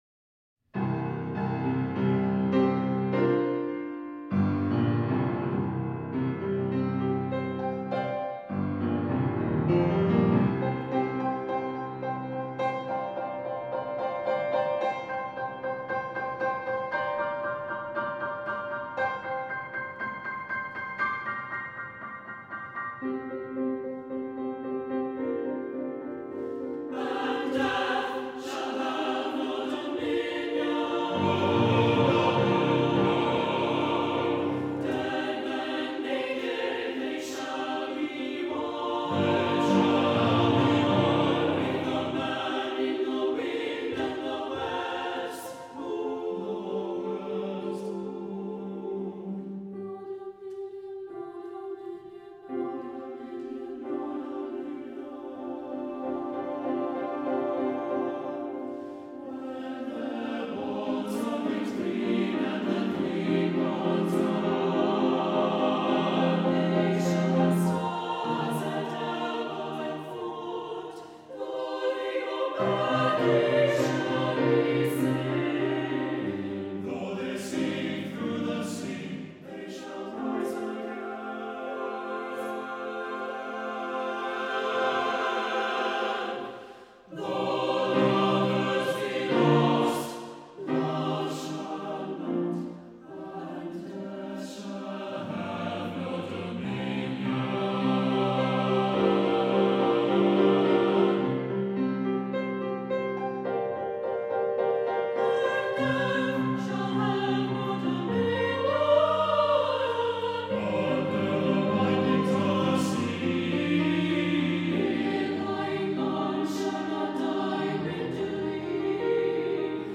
for SATB Chorus and Piano (2010)